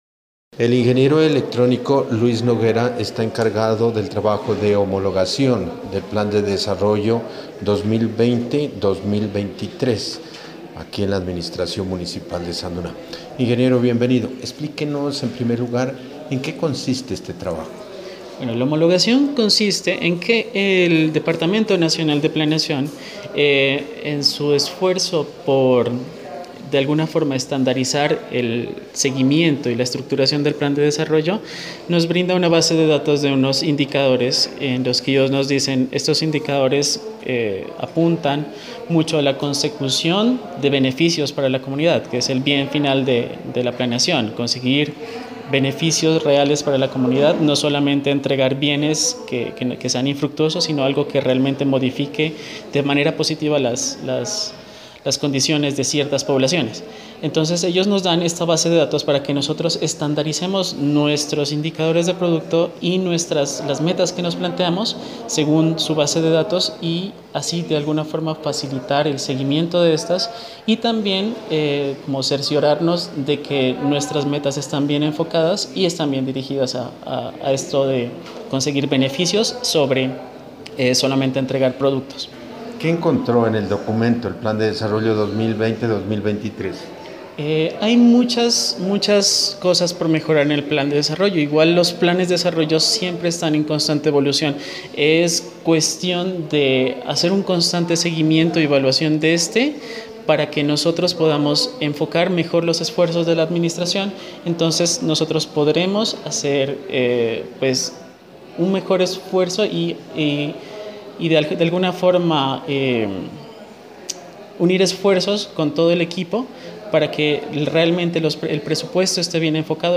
En la entrevista el profesional se refirió a los indicadores, al alcance del presupuesto, a la experiencia del equipo, a la obligatoriedad de disponer de recursos para determinados sectores, a la capacidad de gestión, entre otros temas.